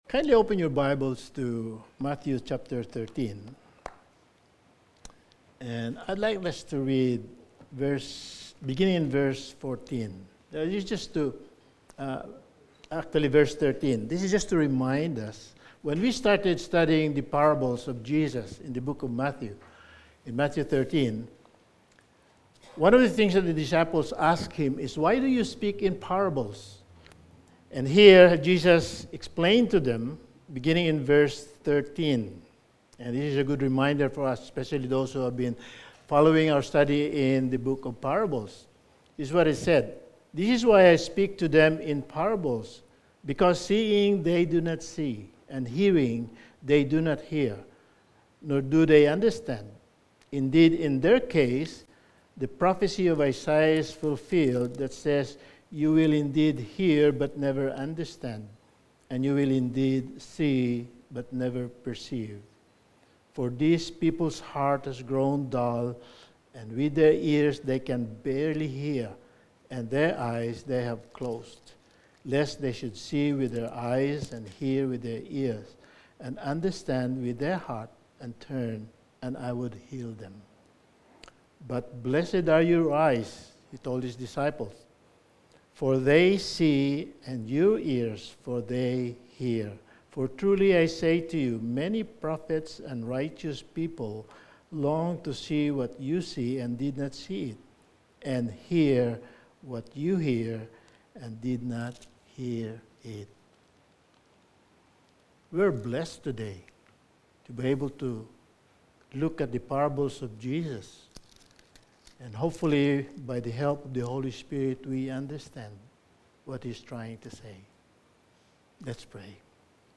Sermon
Service Type: Sunday Evening Sermon